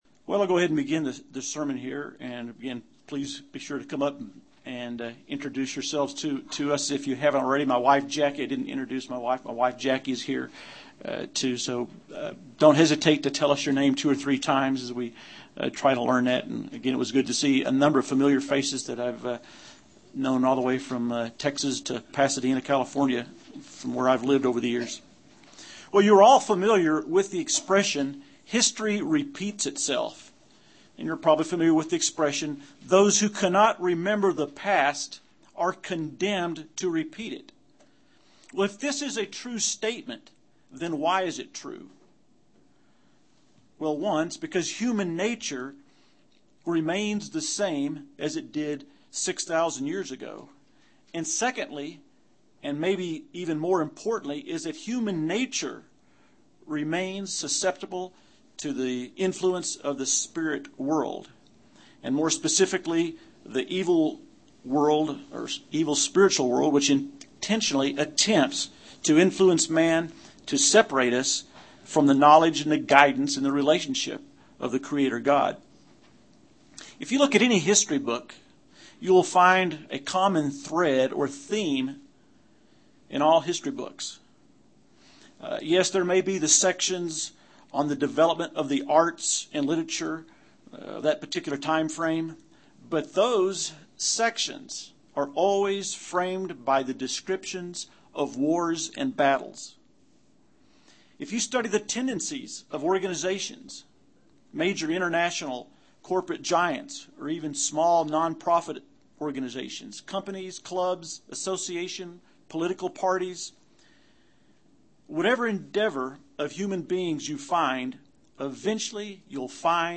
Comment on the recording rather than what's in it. Given in Albuquerque, NM